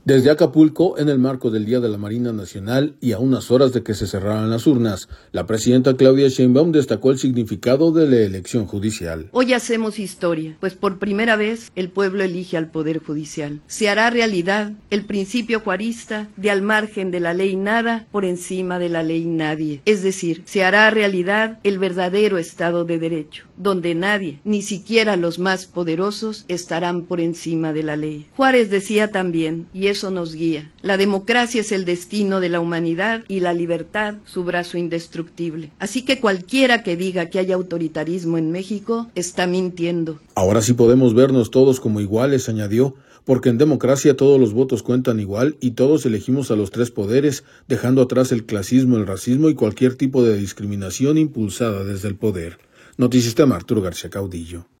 audio Desde Acapulco, en el marco del Día de la Marina Nacional y a unas horas de que se cerraran las urnas, la presidenta Claudia Sheinbaum, destacó el significado de la elección judicial.